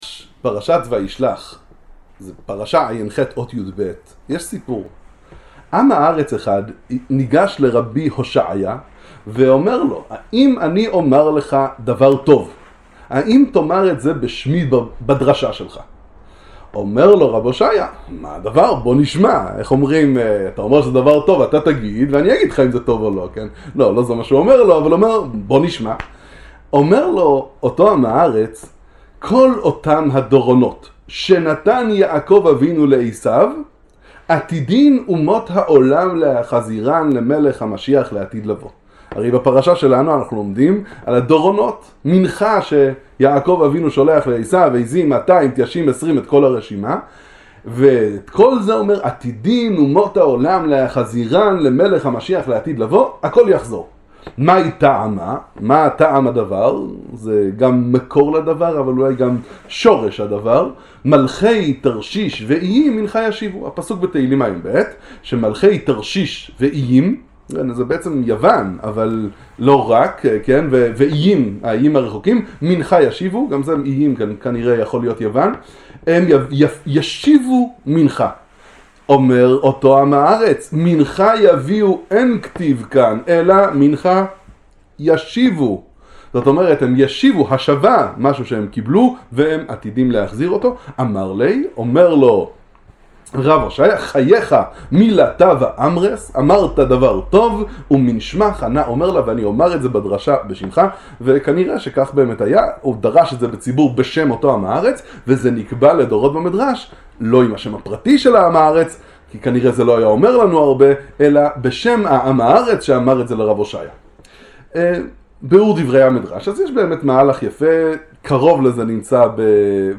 שיעור בספרי איזביצא ראדזין על פרשת השבוע